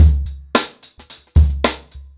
HarlemBrother-110BPM.3.wav